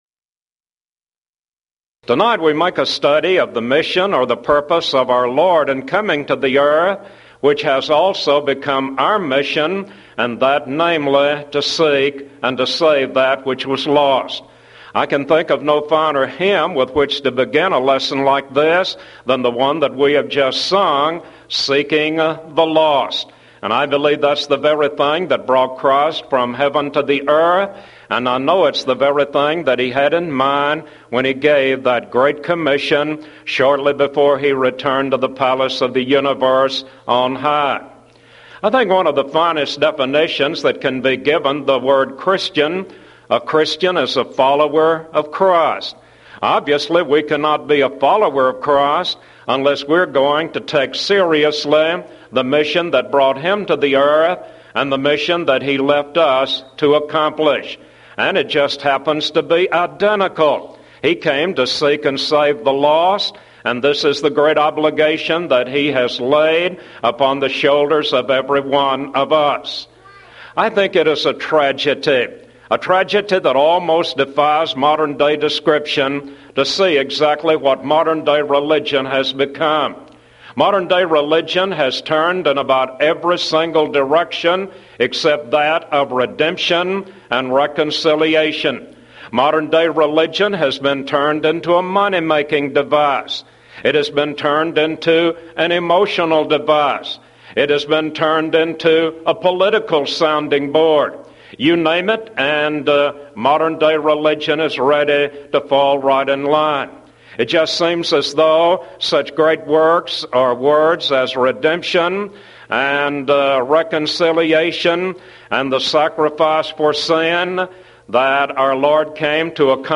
Series: Mid-West Lectures Event: 1994 Mid-West Lectures Theme/Title: To Seek and Save the Lost